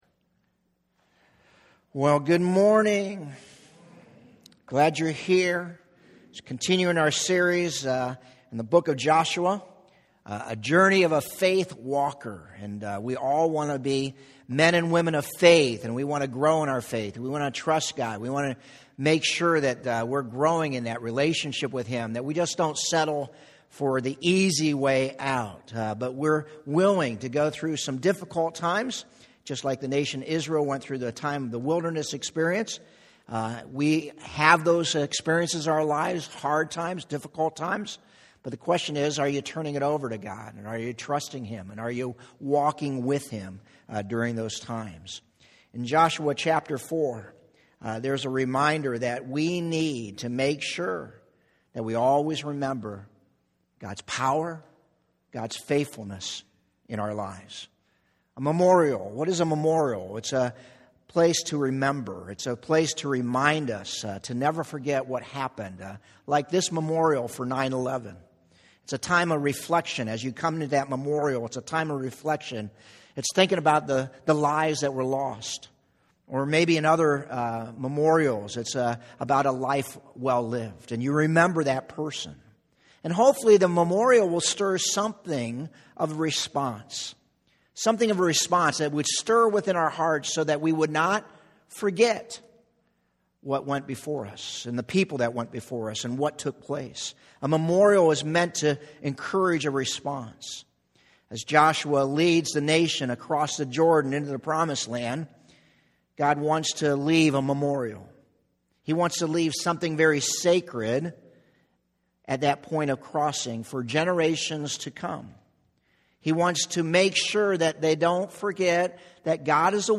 Medina Community Church Sermons
Sun Sermon Recording  9_30_18a.mp3